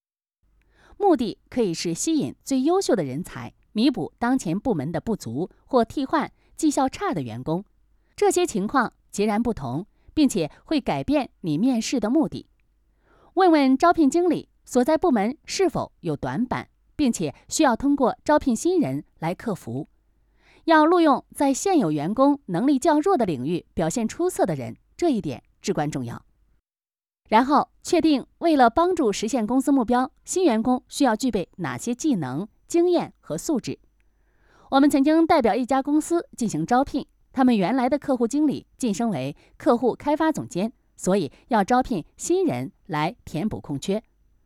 Chinese_Female_043VoiceArtist_4Hours_High_Quality_Voice_Dataset